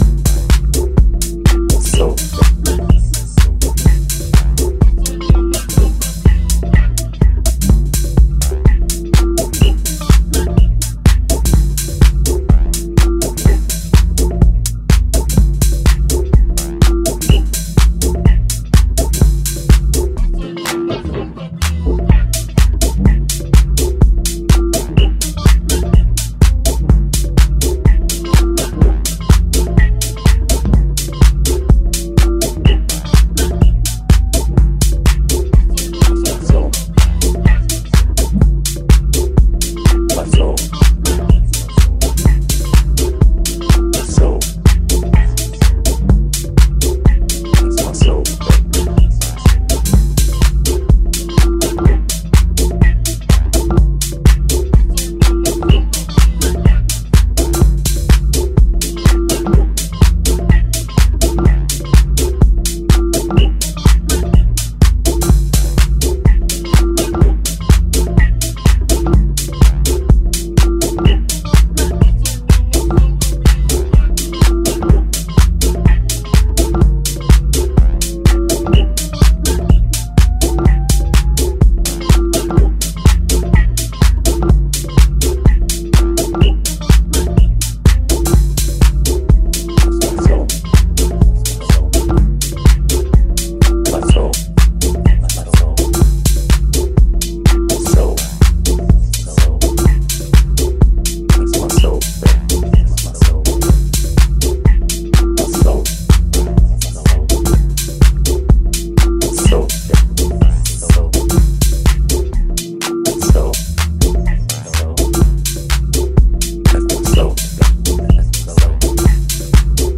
detail and timeless club music.